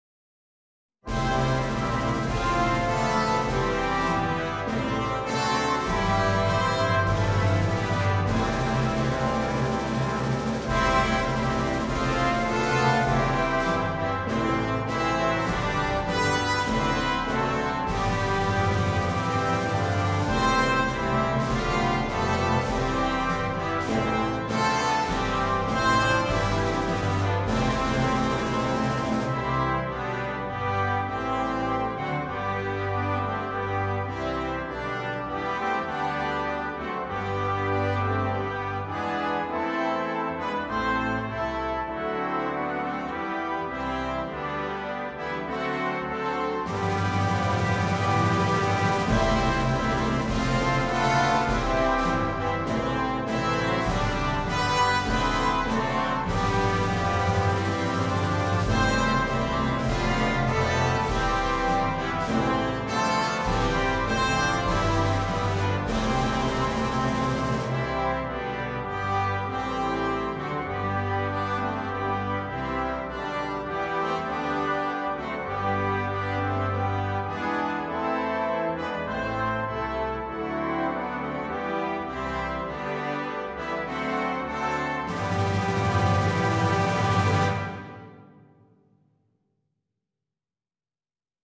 Full Concert Band
KeyC Major